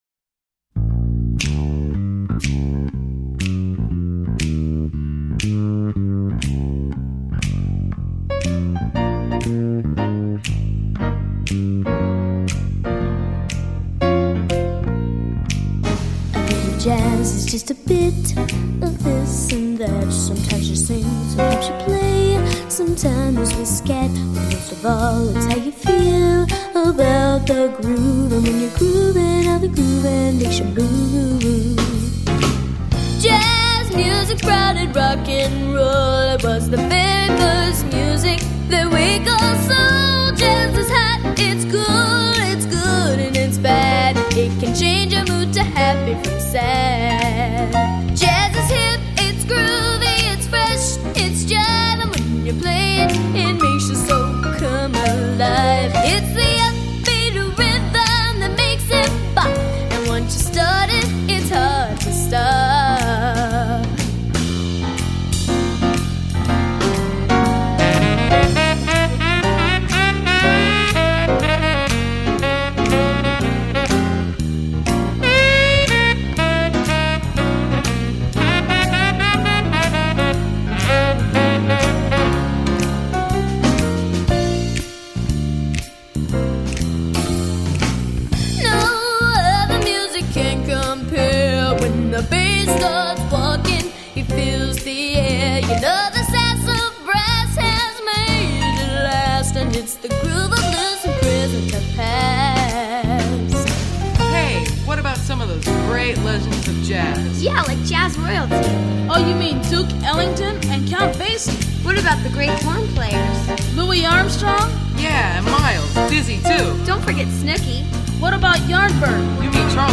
Genre: Pop-Rock.